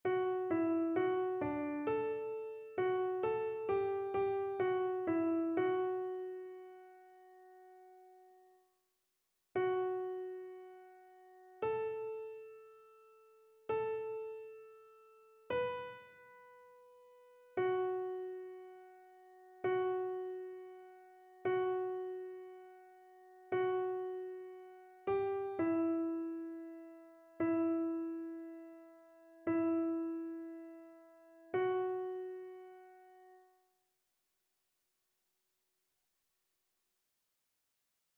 Chœur